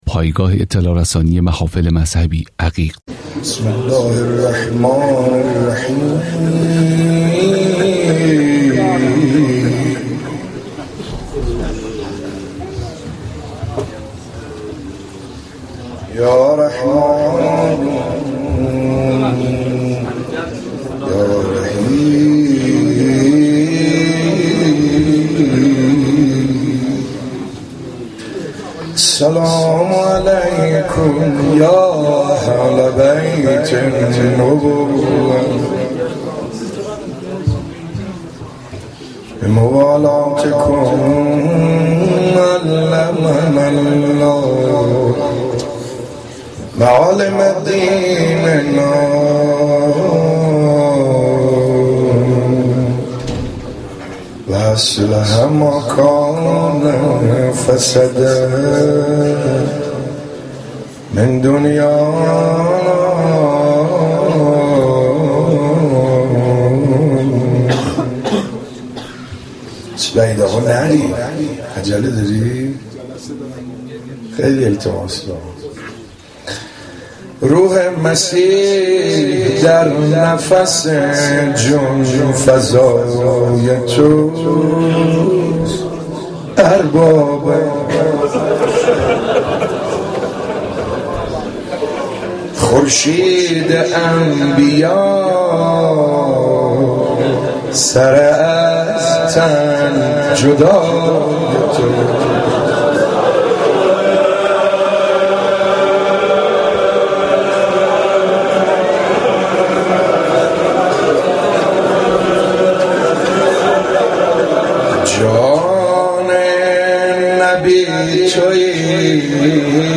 صوت / روضه علی اکبر (ع) در شب هجدهم صفر